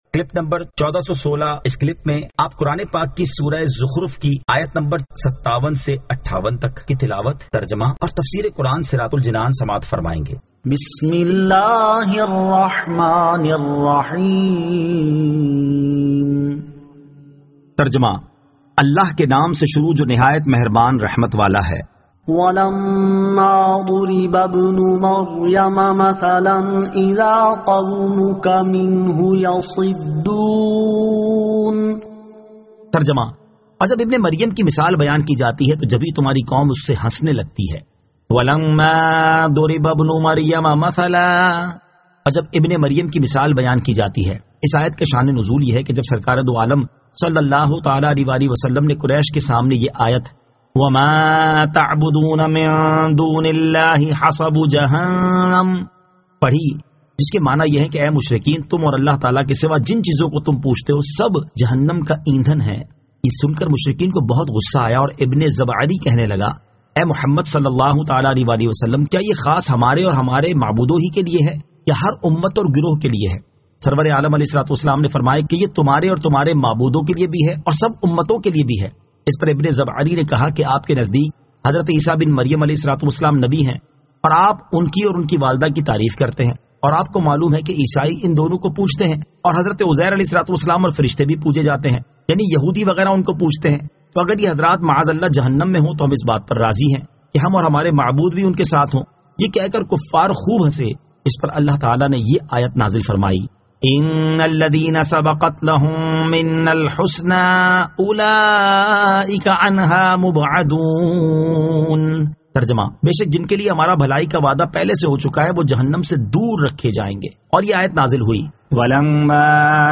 Surah Az-Zukhruf 57 To 58 Tilawat , Tarjama , Tafseer